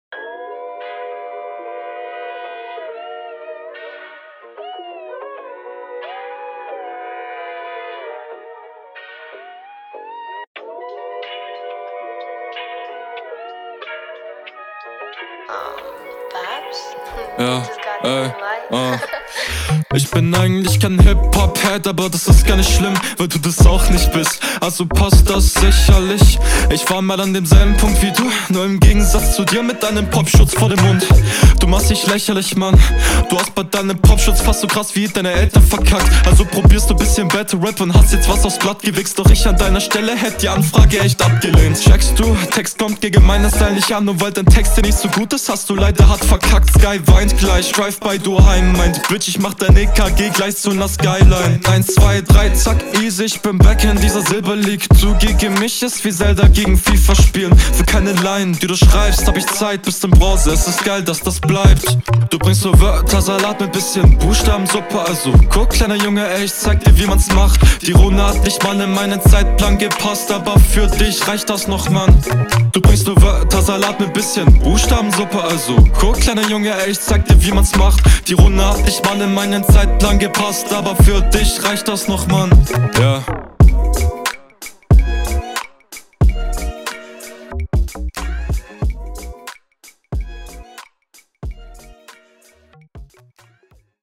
Die paar Atmer hättste aber noch raushauen können nh Sonst aber netter Sound und kann …